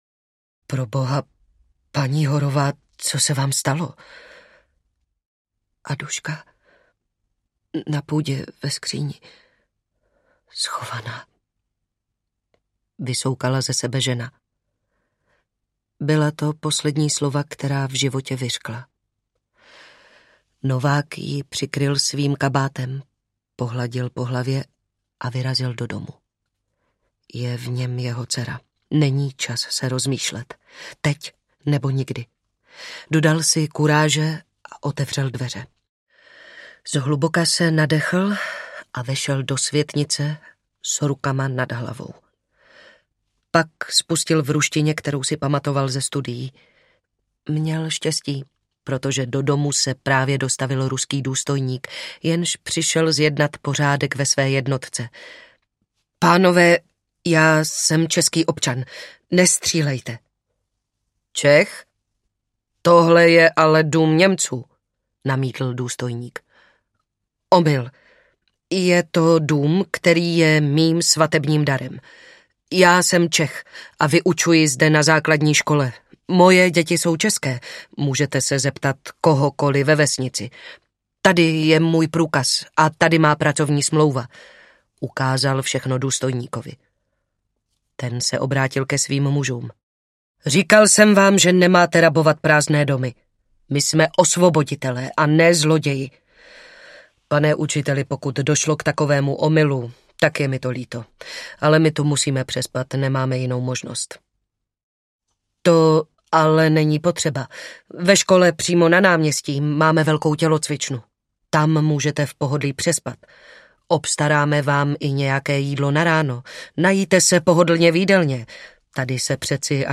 Ada audiokniha
Ukázka z knihy